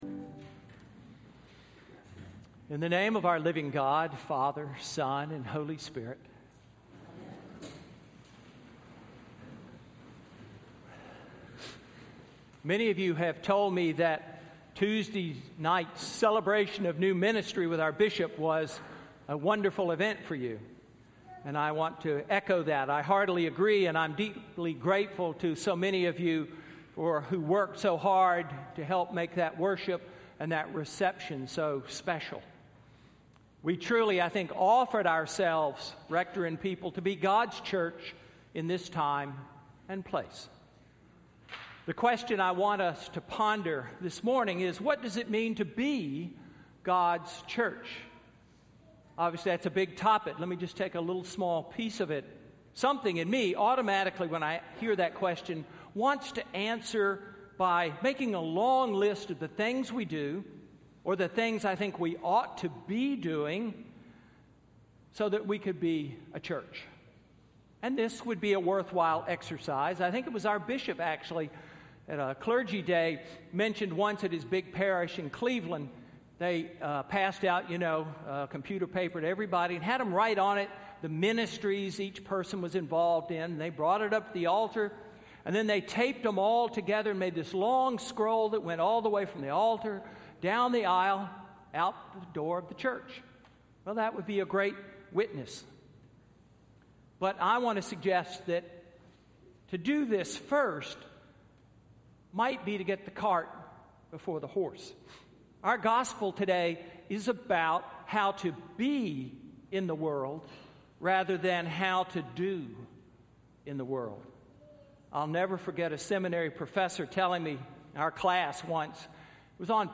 Sermon–October 18, 2015